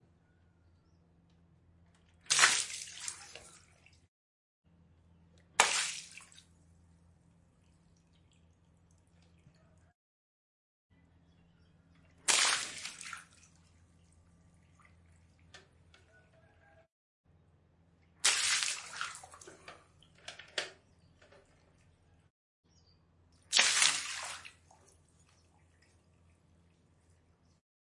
气体/液体 " 水长倒在瓷器上
描述：长时间溅水冲到淋浴间的瓷质地板上。录音设备：TascamDR40（内置麦克风）
标签： 液体 飞溅 沐浴
声道立体声